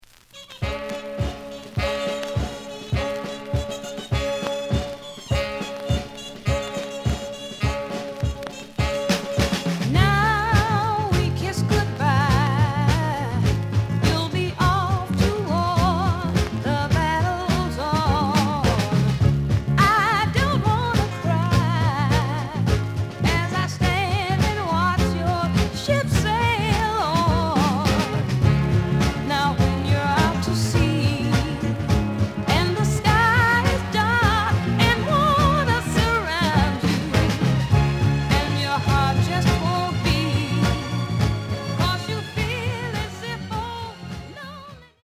●Genre: Soul, 60's Soul